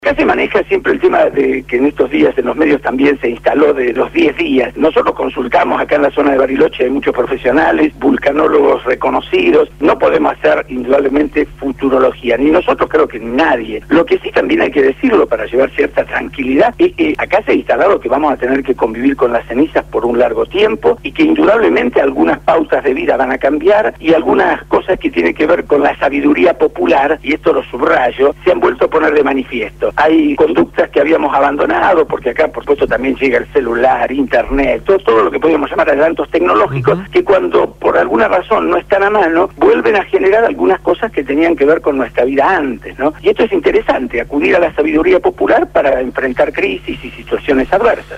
INFORME DESDE LA PATAGONIA: LA ERUPCIÓN DEL VOLCÁN PUYEHUE EN CHILE